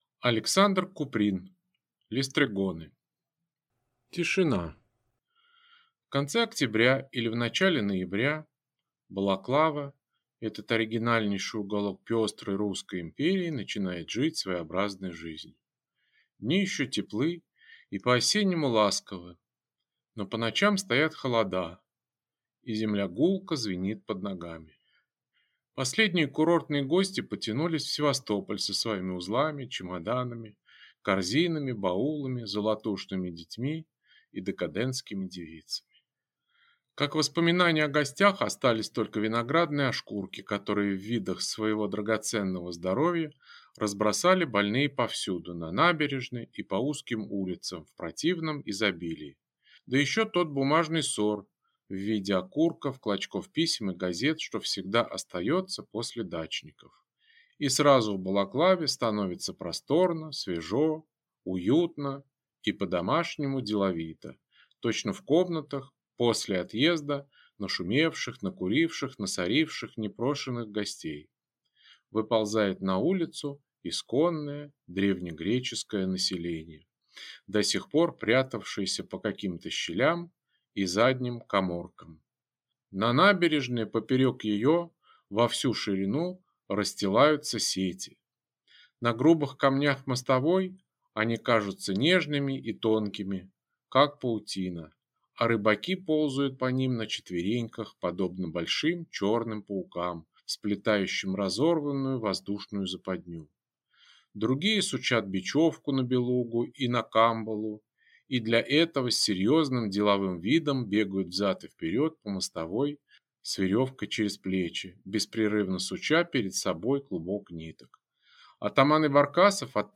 Аудиокнига Листригоны | Библиотека аудиокниг